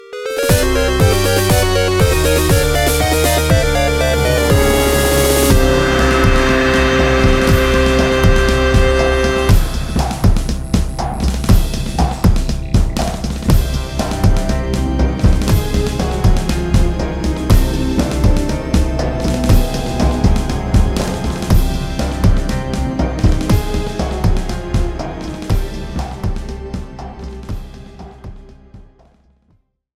Shortened to 30 sec, added fadeout
Fair use music sample